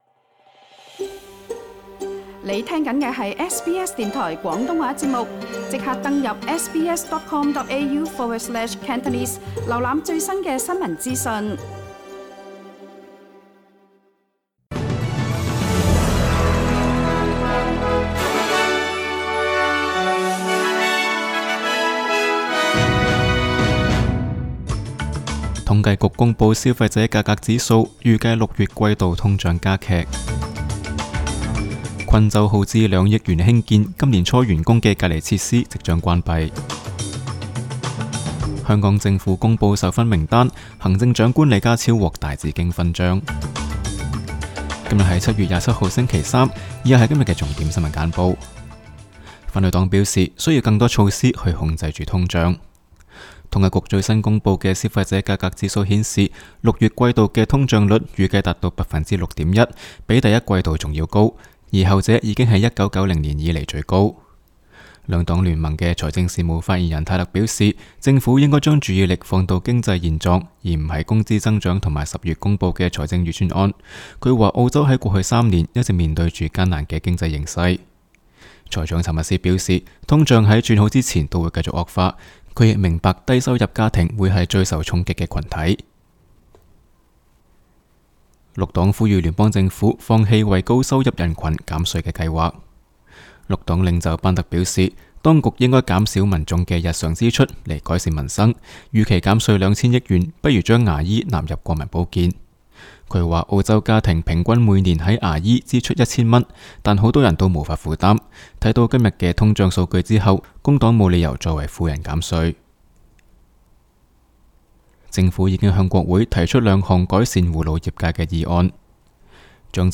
SBS 新闻简报（7月27日）
SBS 廣東話節目新聞簡報 Source: SBS Cantonese